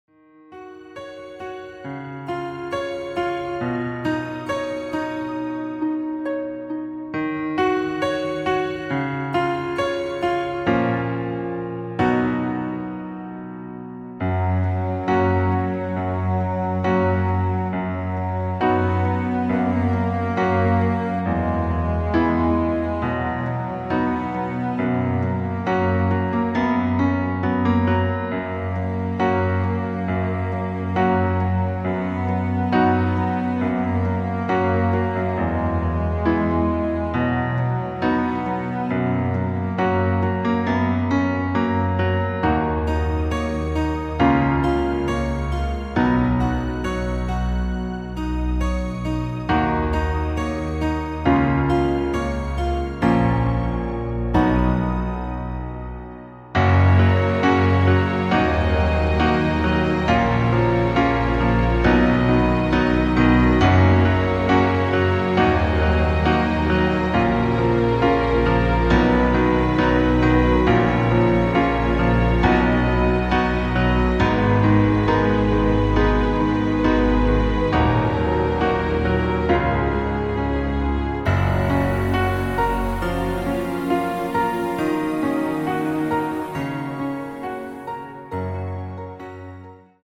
• Tonart:  E Dur, F# Dur
• Art: Klavier Streicher Version
• Das Instrumental beinhaltet keine Leadstimme
Klavier / Streicher